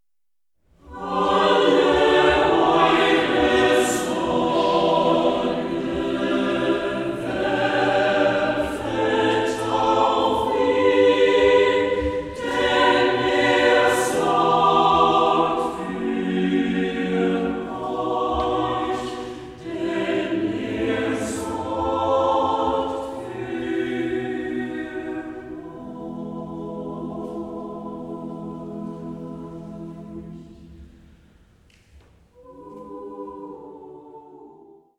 Vokalensemble